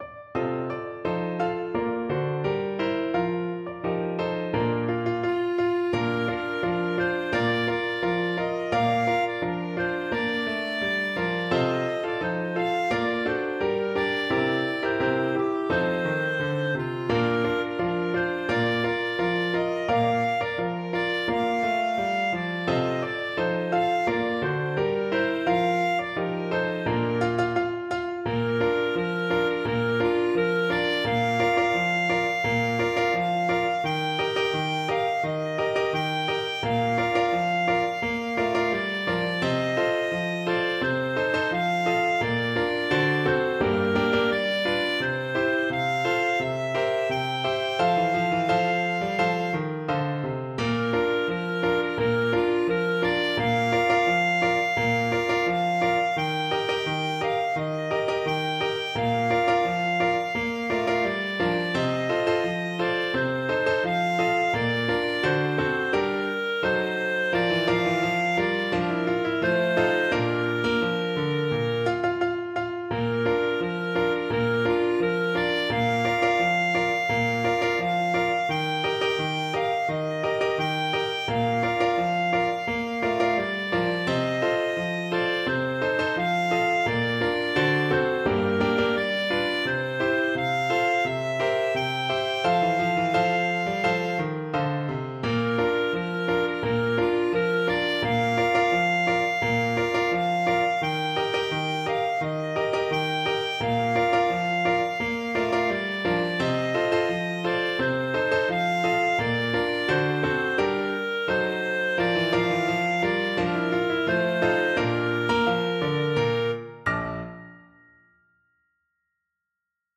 Clarinet
2/2 (View more 2/2 Music)
Tempo di Marcia = c.86
Bb major (Sounding Pitch) C major (Clarinet in Bb) (View more Bb major Music for Clarinet )